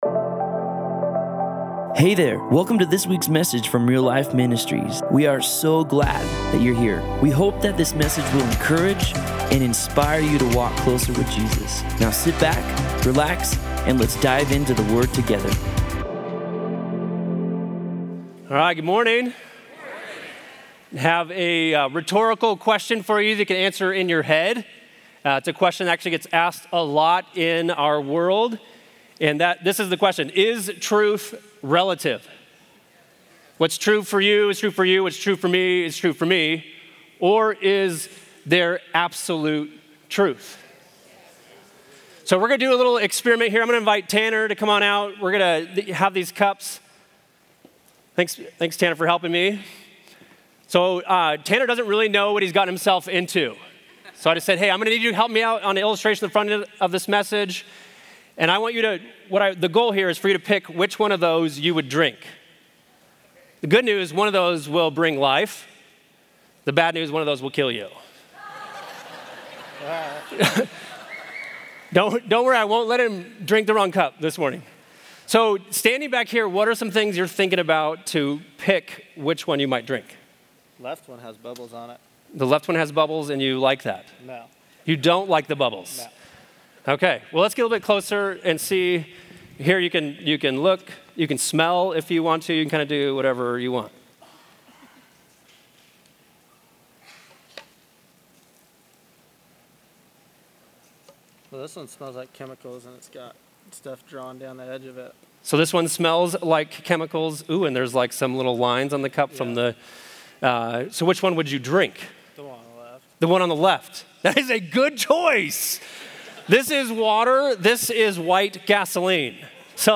Other Sermon in this Series